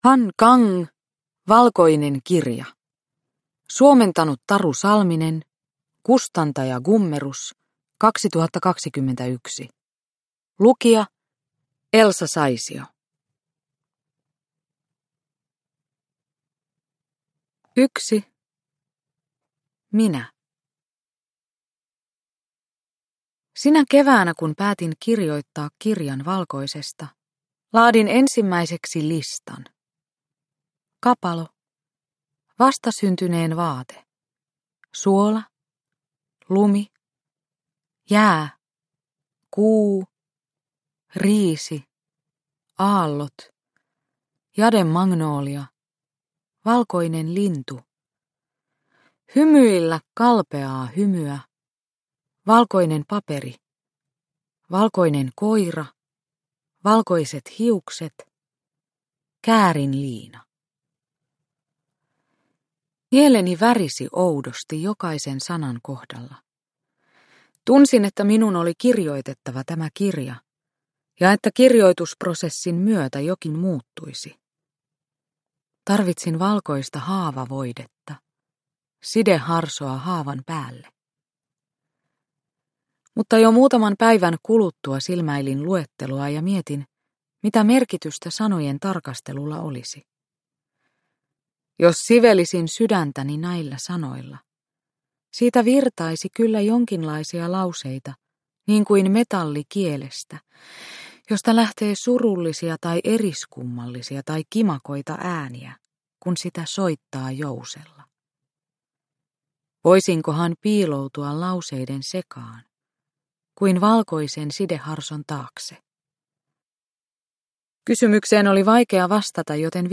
Valkoinen kirja – Ljudbok – Laddas ner
Uppläsare: Elsa Saisio